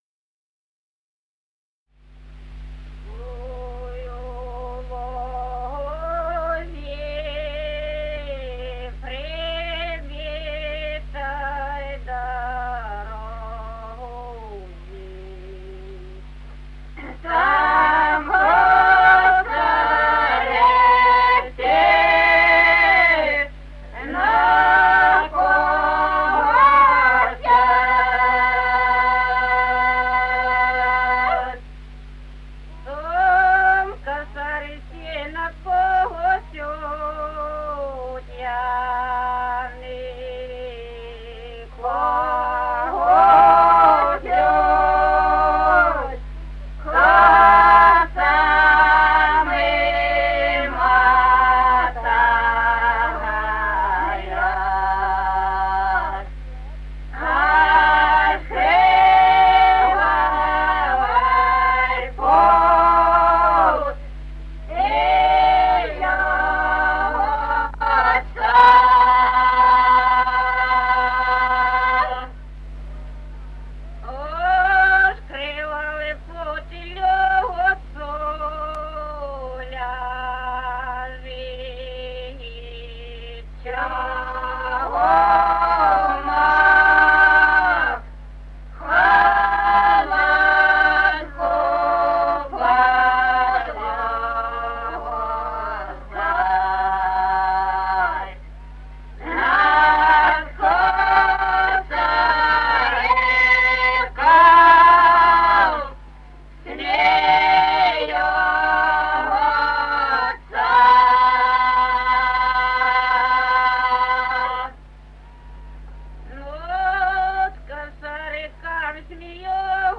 Народные песни Стародубского района «Чтой у лузе», чумацкая.